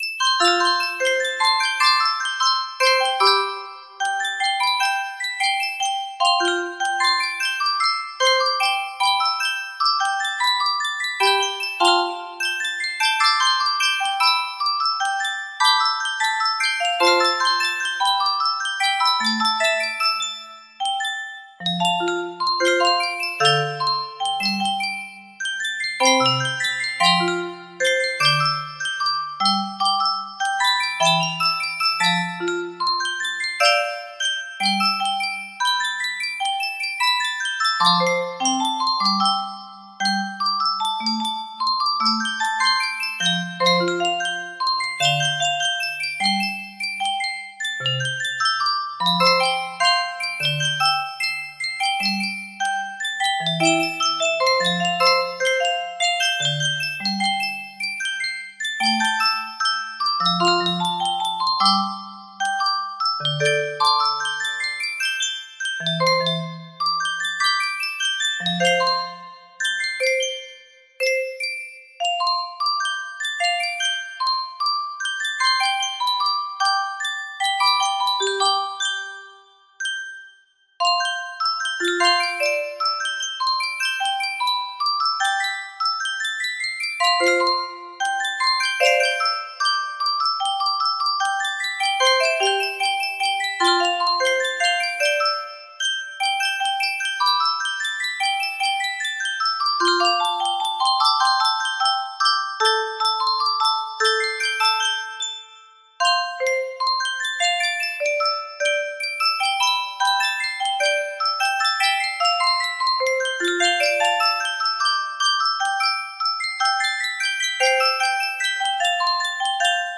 Full range 60
Done, Proper tempo, No Reds.